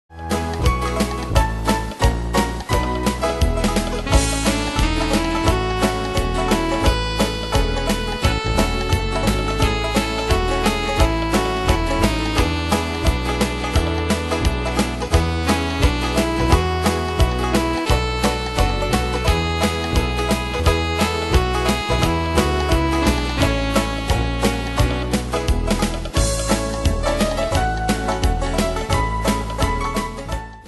Style: Oldies Ane/Year: 1939 Tempo: 174 Durée/Time: 2.36
Danse/Dance: Dixie Cat Id.
Pro Backing Tracks